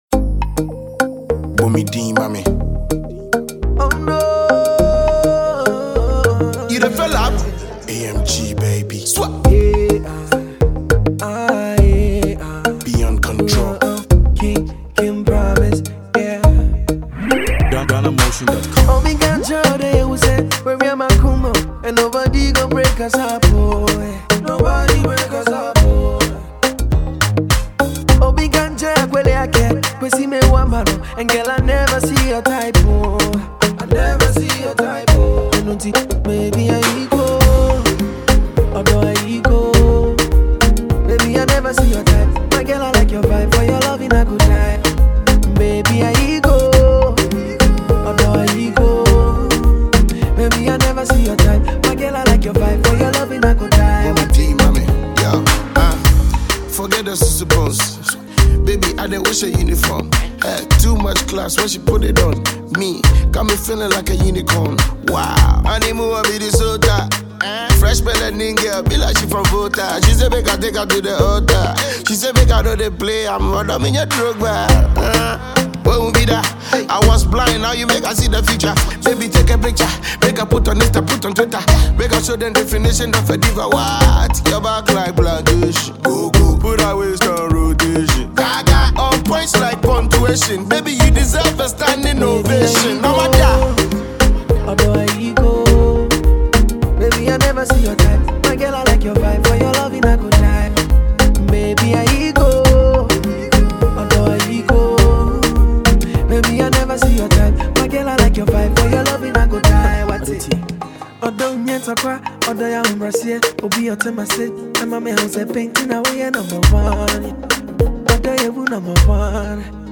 Genre: Hip Hop & Rap